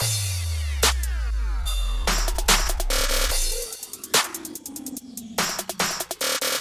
Trap Perc小号帽
Tag: 145 bpm Trap Loops Percussion Loops 1.11 MB wav Key : Unknown